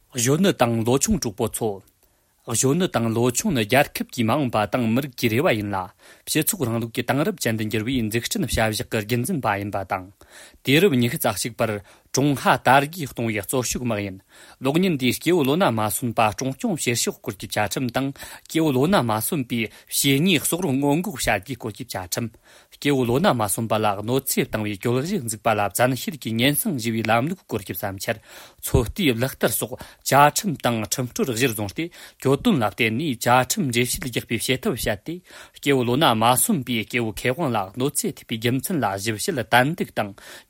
安多藏语-年轻活力